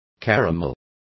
Complete with pronunciation of the translation of caramels.